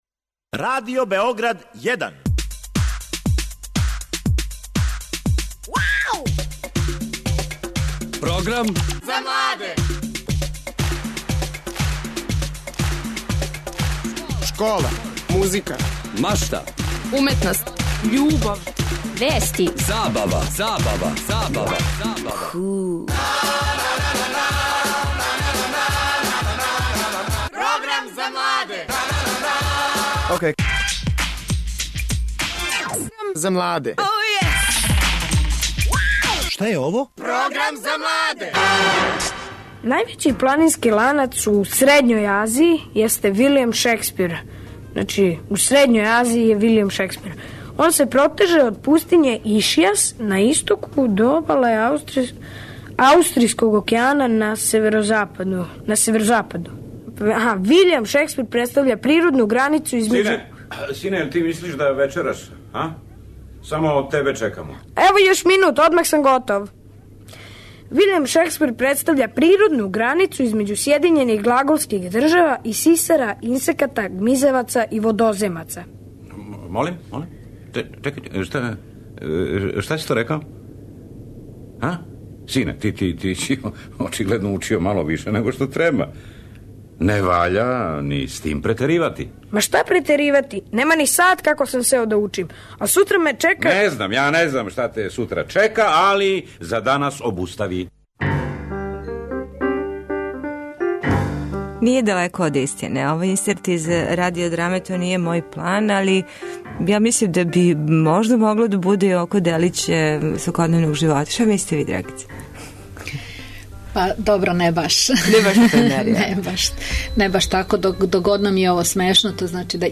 Шта знају млади људи и на ком месту је знање као вредносна категорија у њиховом животу? Гости су млади људи